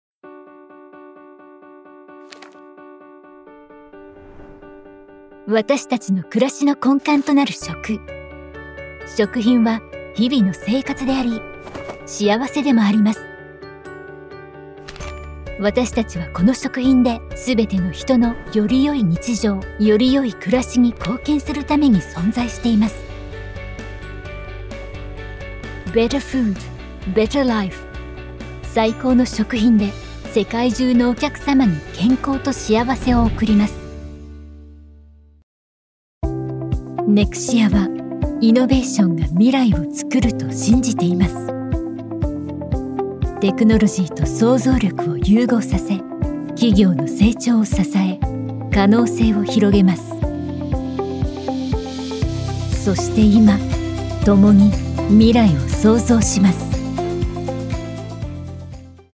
Corporate Narration – Japanese | Professional & Trustworthy
• Neumann TLM 103 condenser microphone